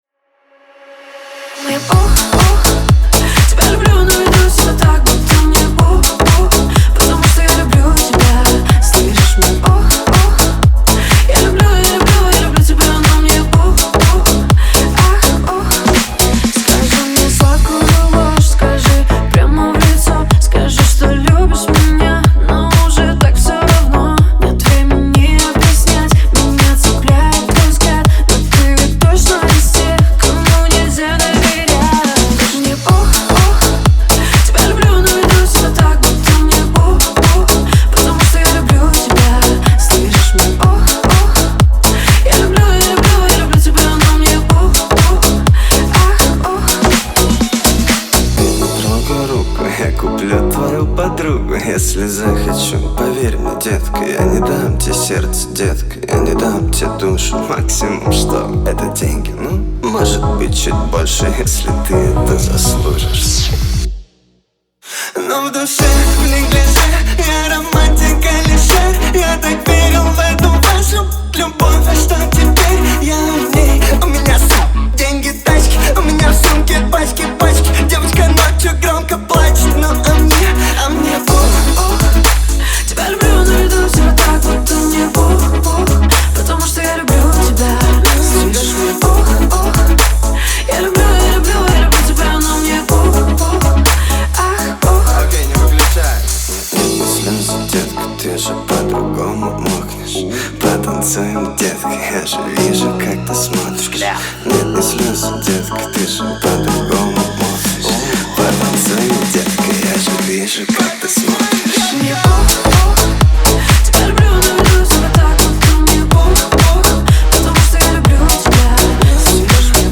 это динамичная и энергичная трек в жанре поп-рэп